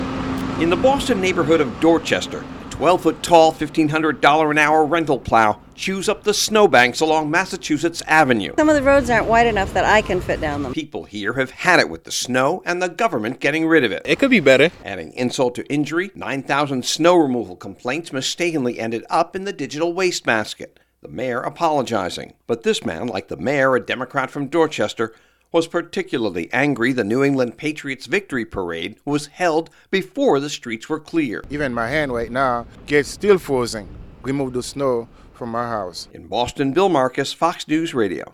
(BOSTON) FEB 21 – BOSTON’S P-R NIGHTMARES CONTINUE TO COMPOUND AS THE SNOW CONTINUES TO FALL.